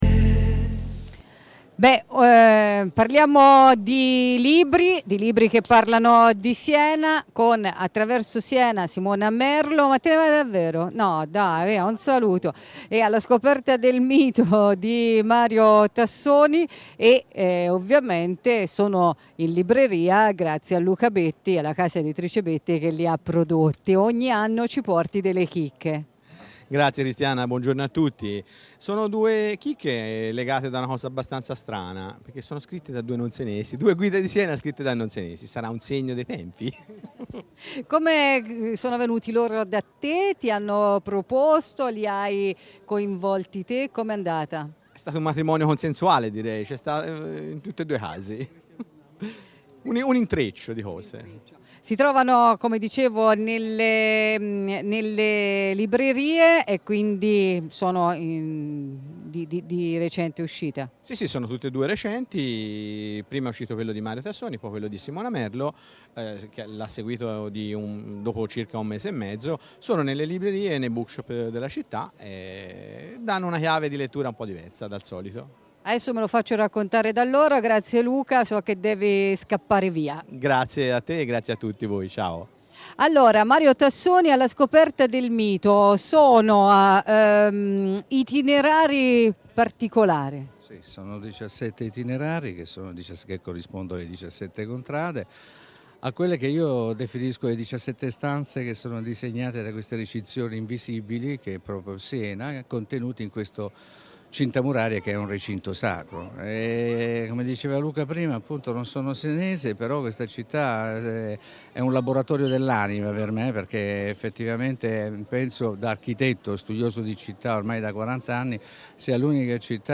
Interviste
In diretta dai palchi